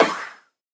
Minecraft Version Minecraft Version 1.21.5 Latest Release | Latest Snapshot 1.21.5 / assets / minecraft / sounds / mob / breeze / deflect3.ogg Compare With Compare With Latest Release | Latest Snapshot
deflect3.ogg